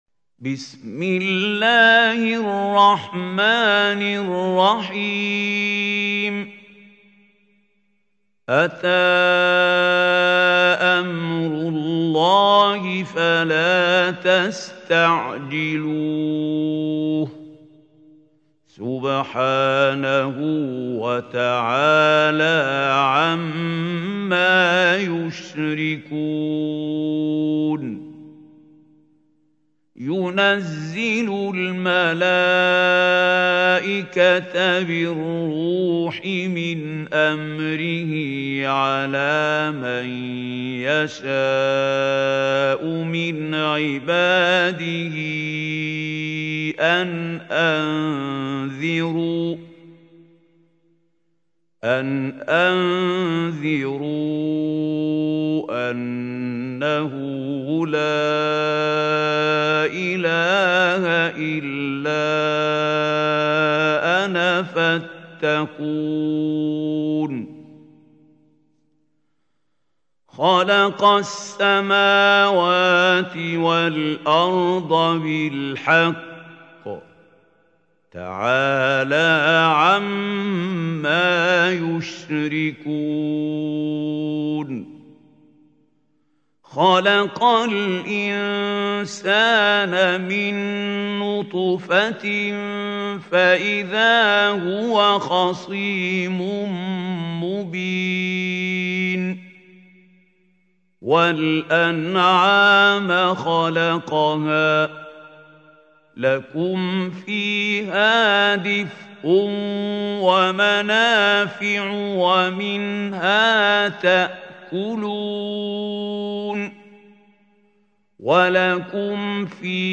سورة النحل | القارئ محمود خليل الحصري